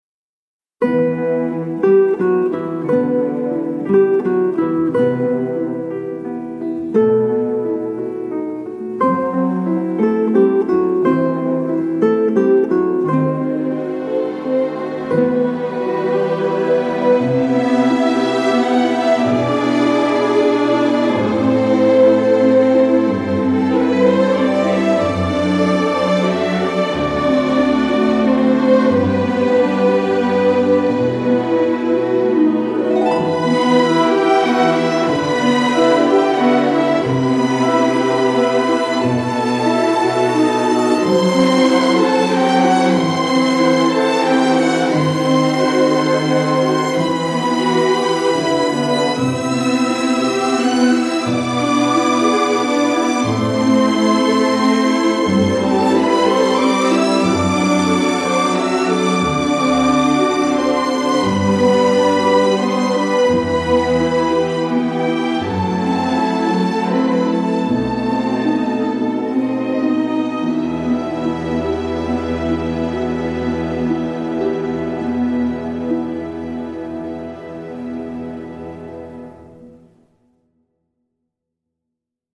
mélancolie de cordes nocturnes et urbaines
mélancolie romantique
mélancolie à la fois intime et tragique
lyrique et mélodique